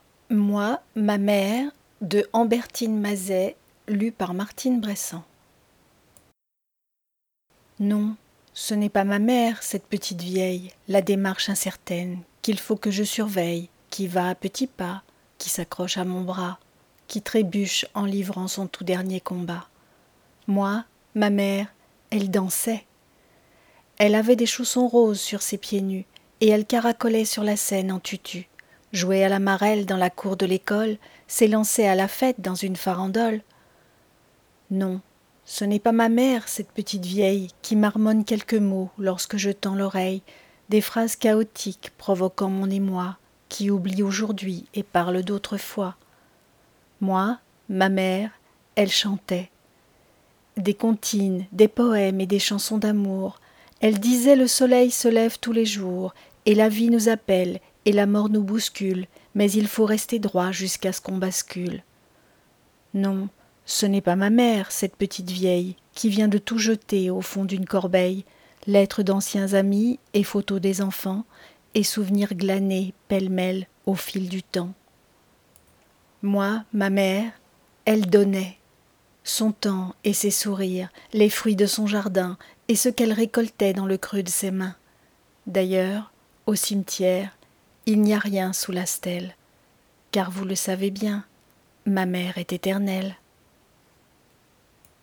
Lecture à haute voix - Moi, ma mère...
POEME